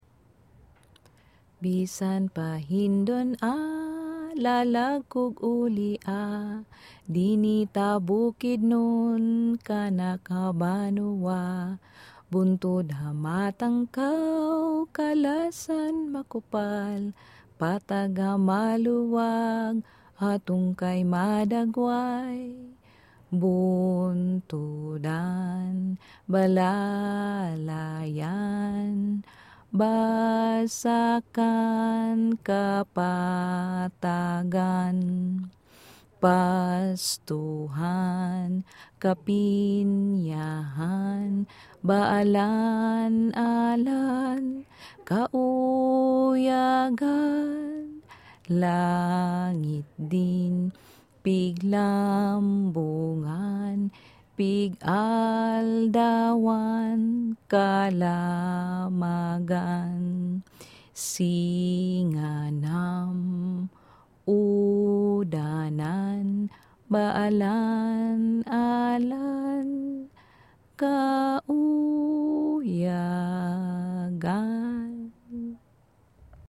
binukid hymn.mp3